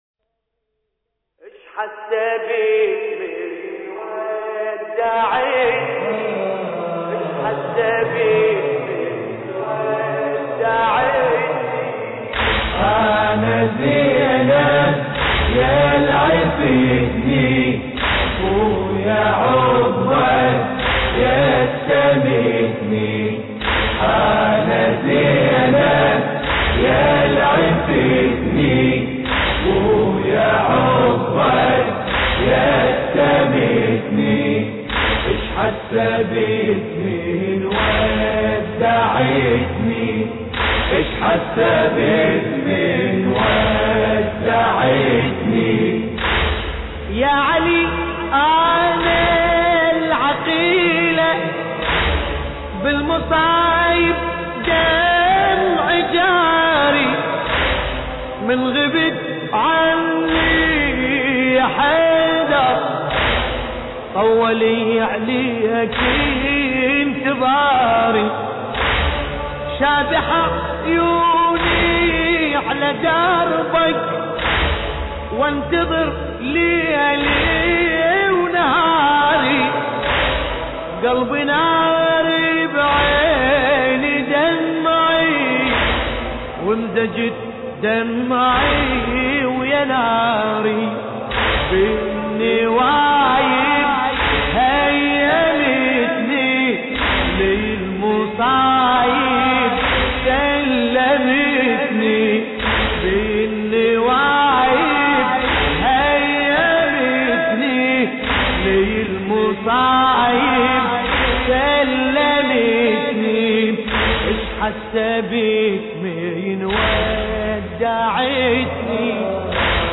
مراثي زينب الكبرى (س)